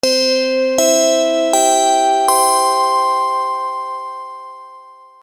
/ G｜音を出すもの / G-01 機器_チャイム
チャイム 04-01 シンセ クリアな音色 アナウンス開始
『ピンポンパンポン』